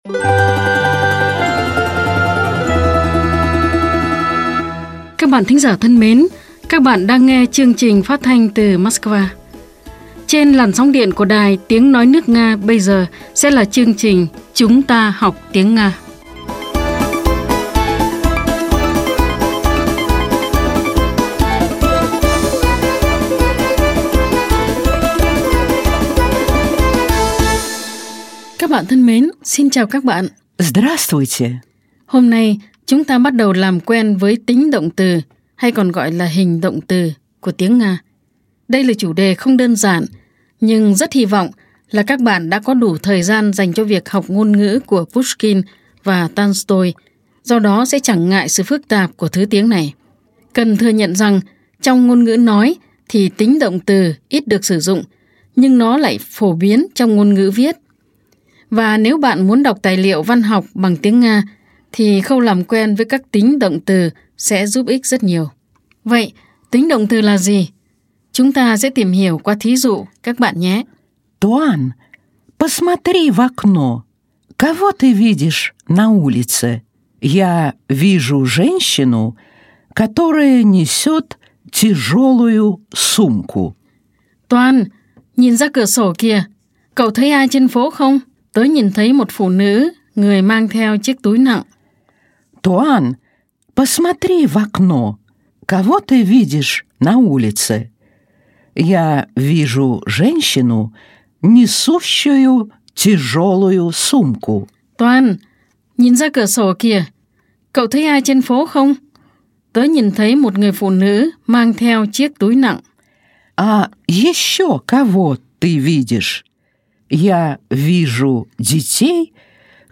Bài 81 – Bài giảng tiếng Nga
Nguồn: Chuyên mục “Chúng ta học tiếng Nga” đài phát thanh  Sputnik